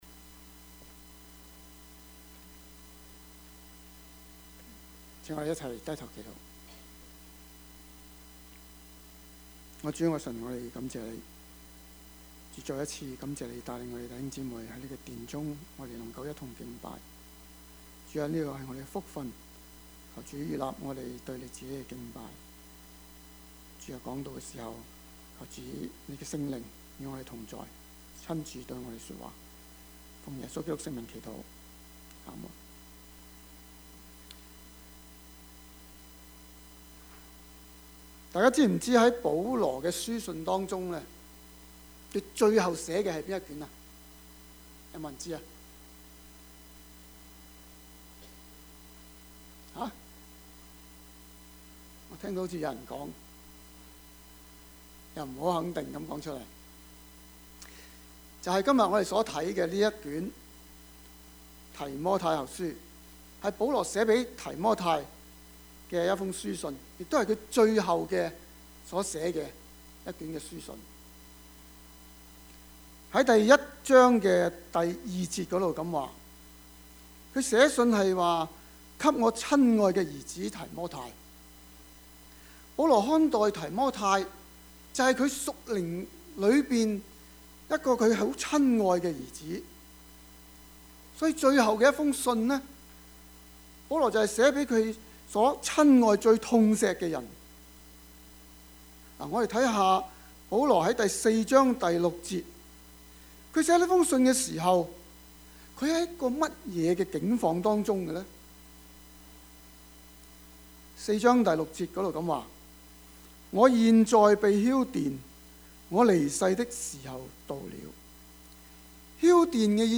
Topics: 主日證道 « 主的憐憫 不要怕, 只要信 »